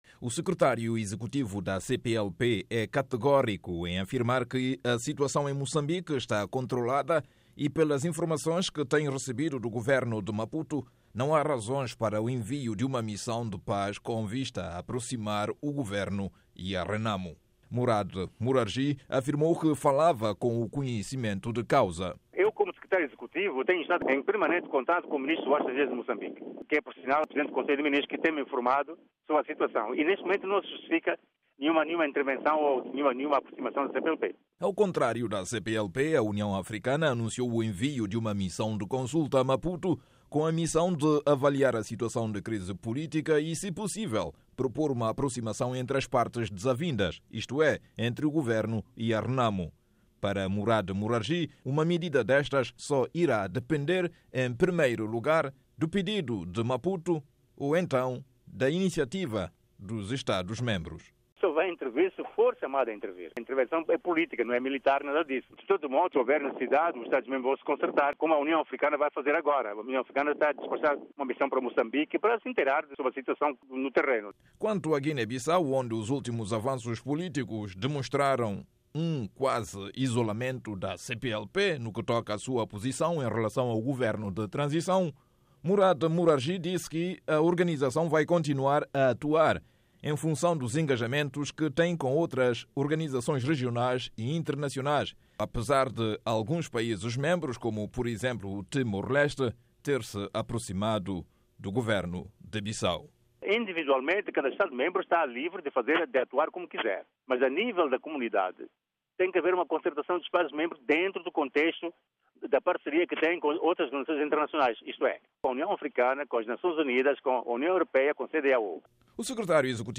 Murade Murargy Secretário-executivo da CPLP